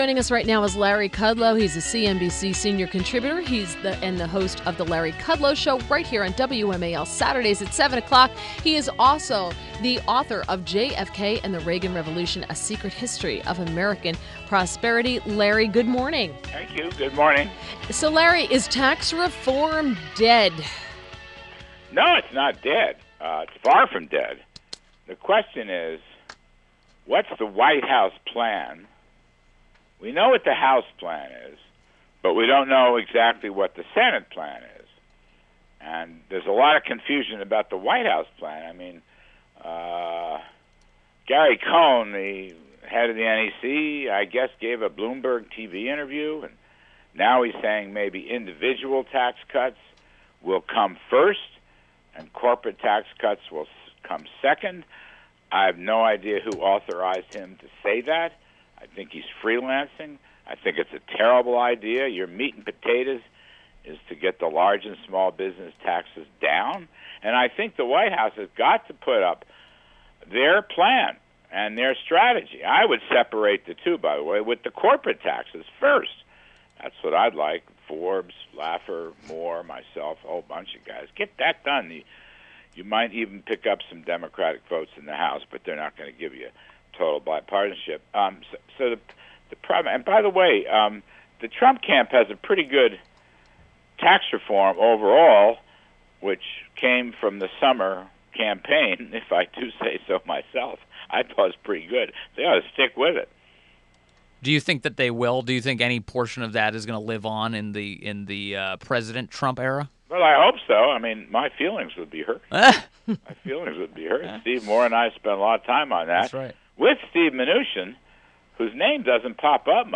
WMAL Interview - LARRY KUDLOW - 04.11.17